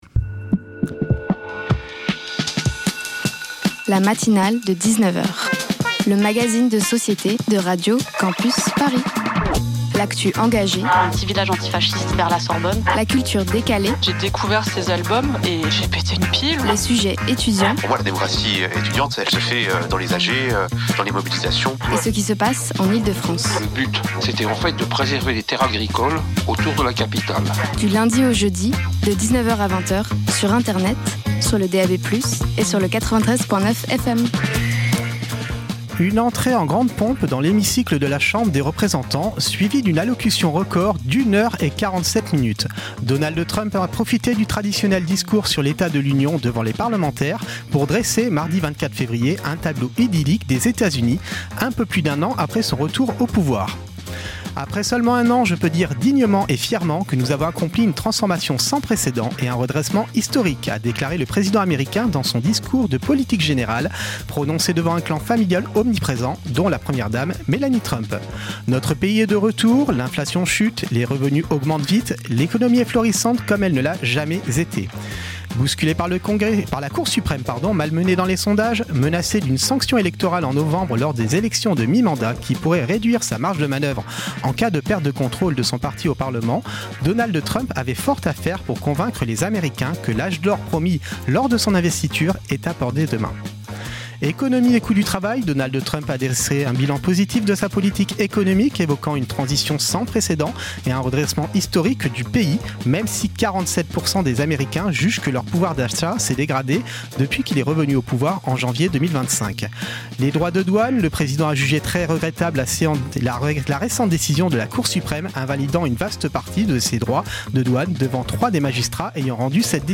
Type Magazine Société Culture